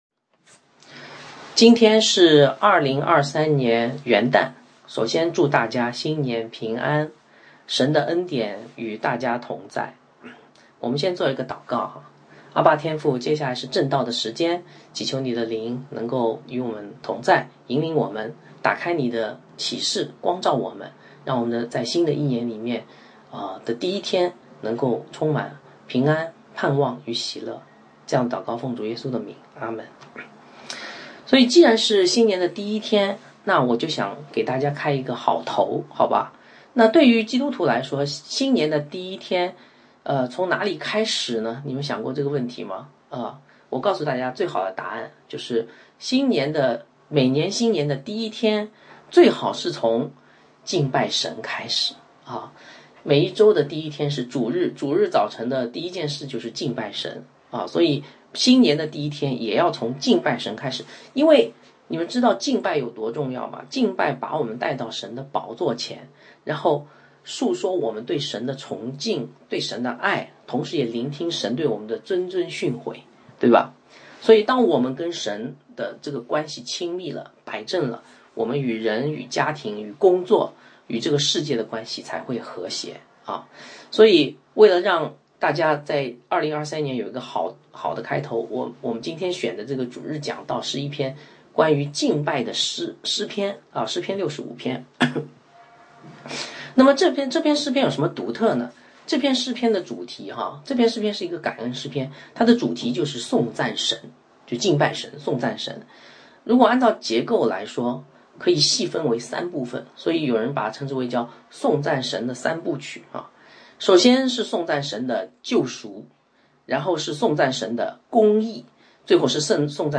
新年讲章：公义的恩典（诗篇65）